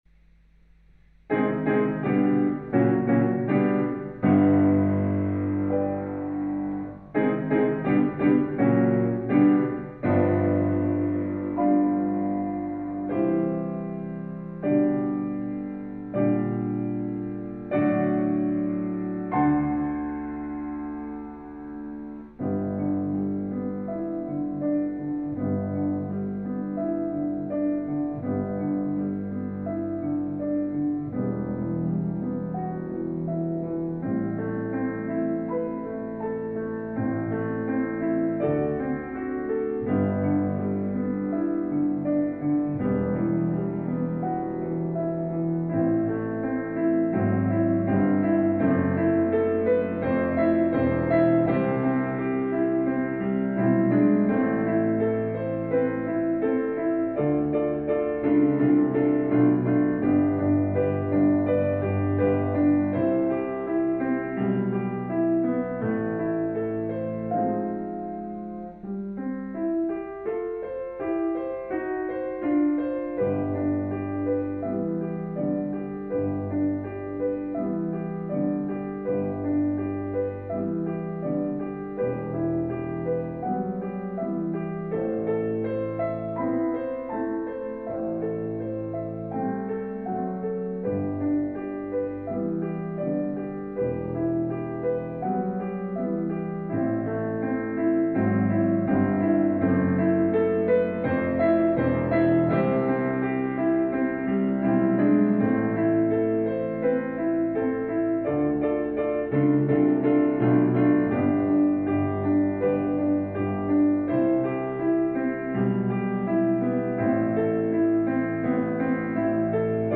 • piano accompaniment